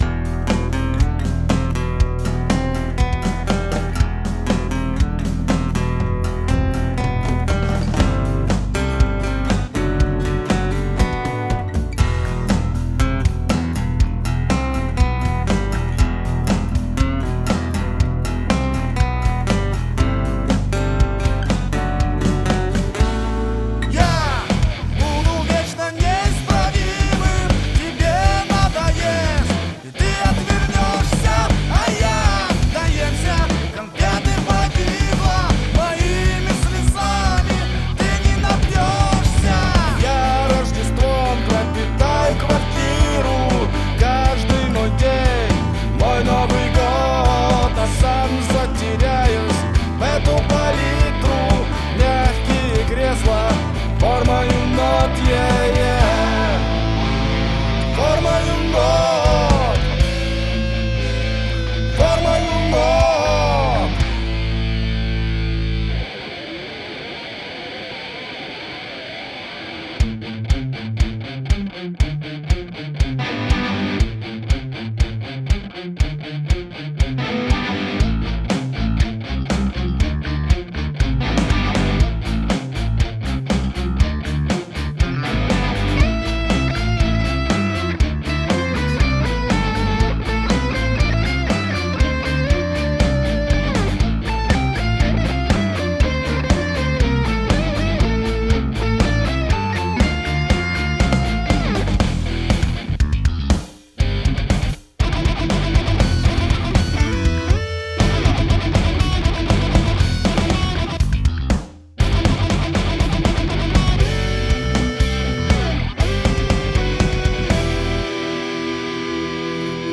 В моем доме (Rock)
В новом треке перезаписаны все гитарные партии, применены другие ударные, бас, некоторые синты, что-то добавлено, чего раньше не было. Вокал оставил тот же.
Мастеринг по понятным причинам еще не делался - рано.